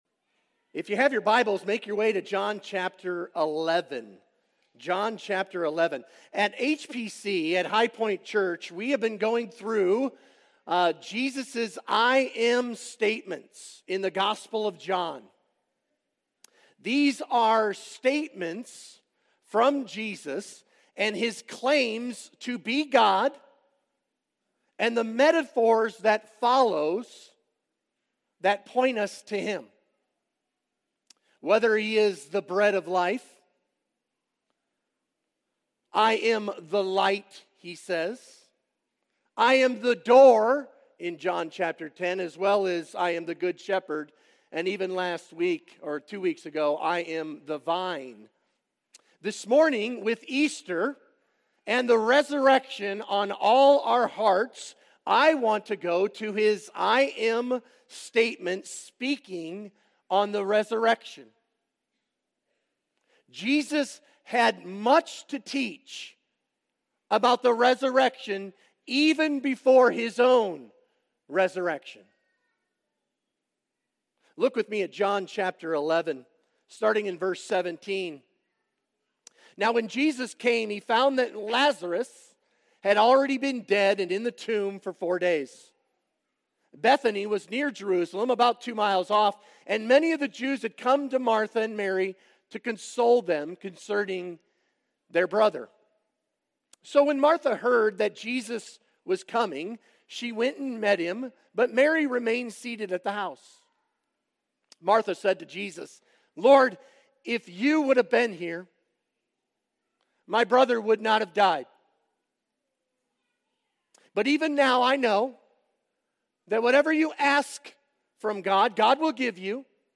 Sermon Questions Read John 11:17-44.